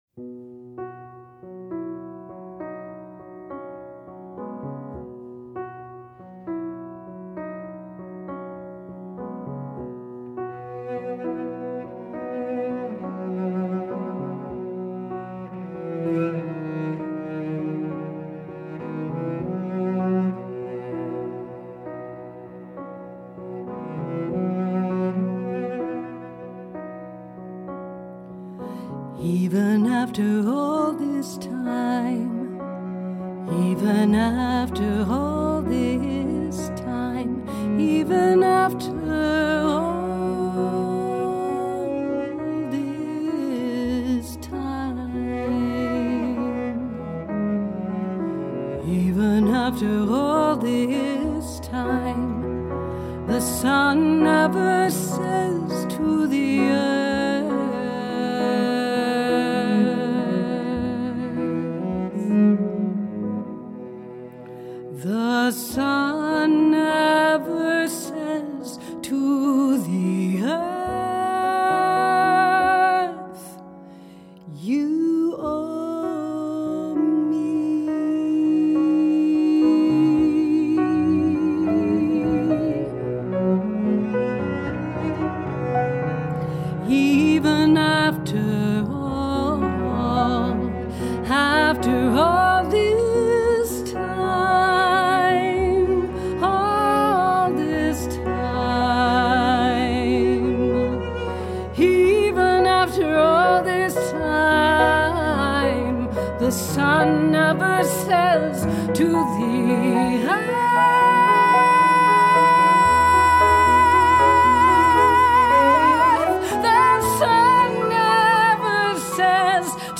A tender ballad of unconditional love.
Low voice, piano